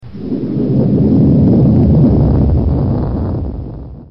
earth quake final